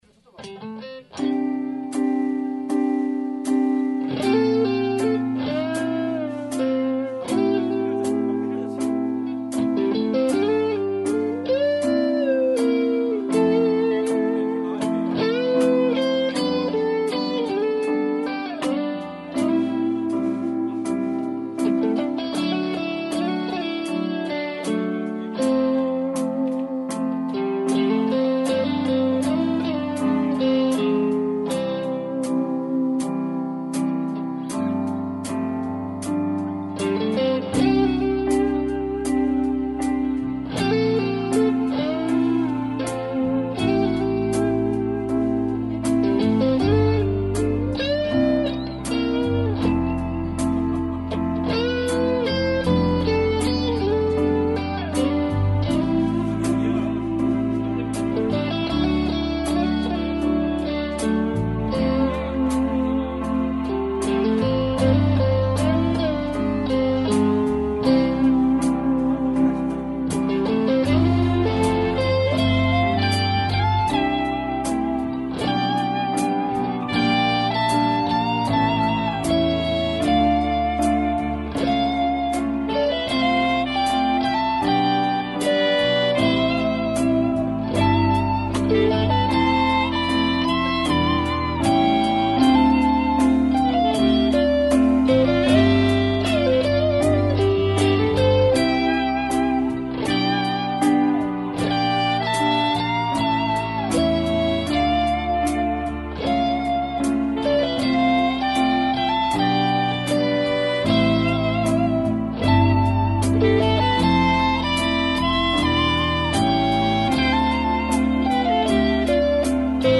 Praise 합주 녹음
2007-06-16 오전 2:09:00 7월 초 공연 대비 연습 녹음입니다--; 기타 맴버도 바뀌고 건반이 라이브버젼으로 카피한거기도 하고 해서
초반 드럼 나오기전까지의 거슬리는 하히헷 소리는 느려지는 템포를 막기위한 어쩔수 없는 선택이었습니다ㅠ